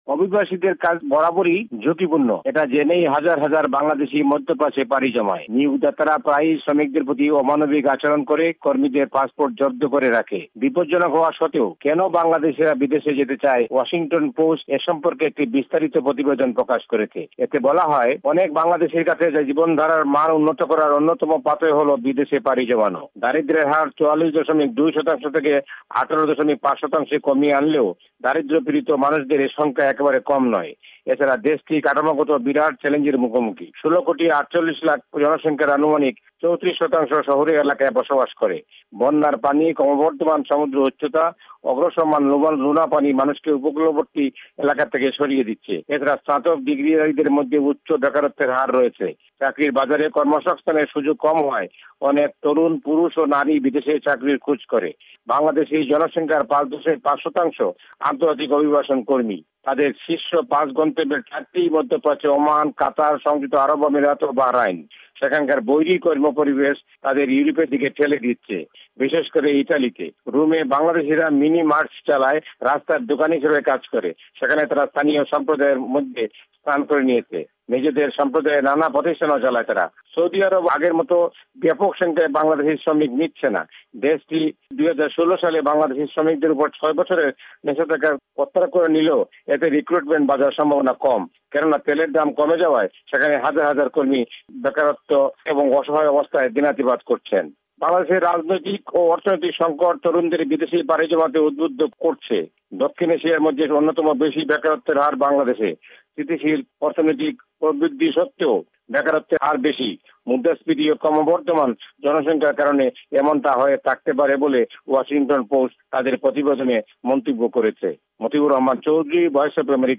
ইউরোপের অবৈধ অভিবাসিদের প্রধান উৎস বাংলাদেশ, সিরিয়া বা যুদ্ধ বিধ্ধস্থ দেশের মানুষ নয়। ওয়াশিংটন পোষ্ট এ নিয়ে করা একটি প্রতিবেদন সম্পর্কে ঢাকা থেকে রিপোর্ট পাঠিয়েছেন মতিউর রহমান চৌধুরী।